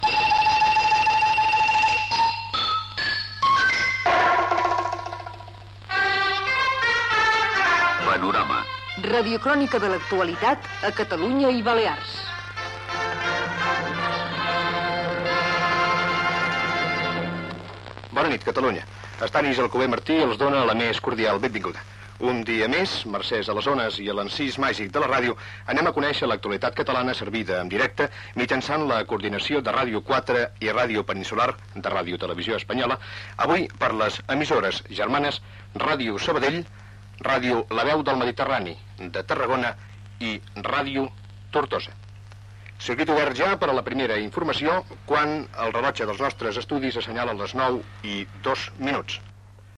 Careta del programa, inici de l'informatiu amb la benvinguda, emissores connectades i hora.
Informatiu
Espai de Ràdio 4 emès en connexió amb Ràdio Peninsular, La Veu del Mediterrani (Tarragona), Ràdio Tortosa i Ràdio Sabadell.